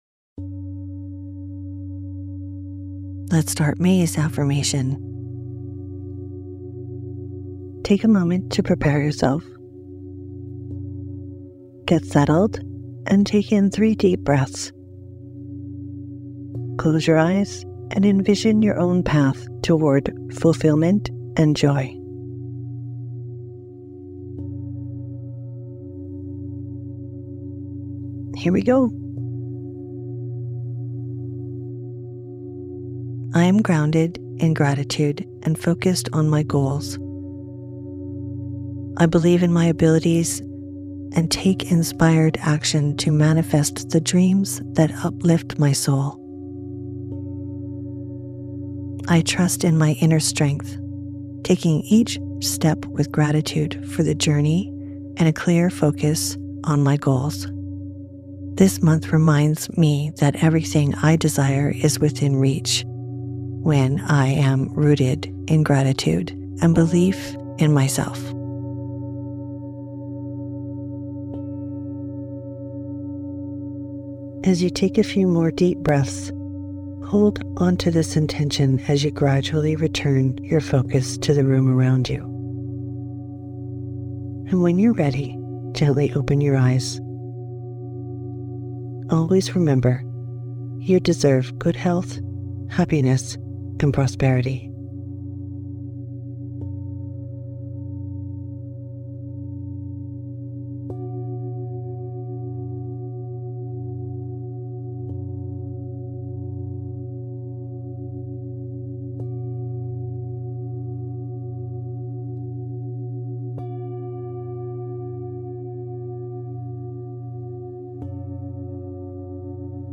MAY AFFIRMATION